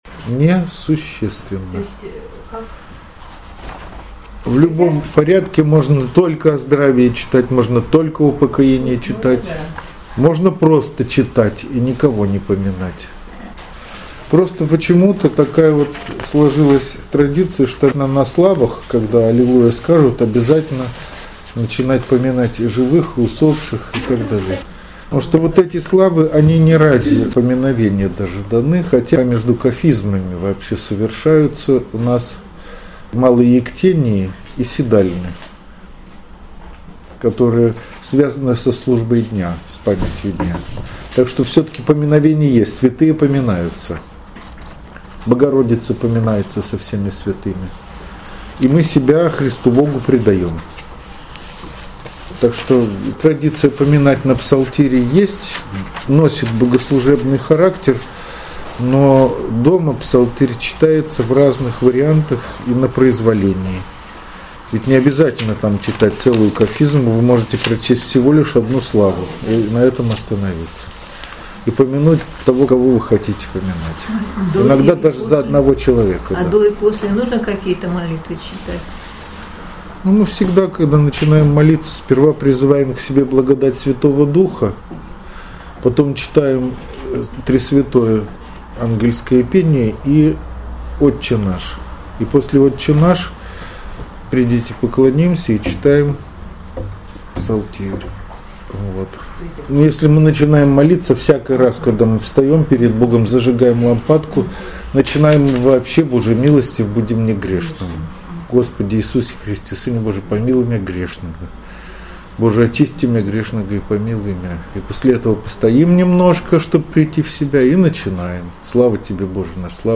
Из лекции 23 ноября 2020 года. Толкование на псалмы. Псалом 118:152-176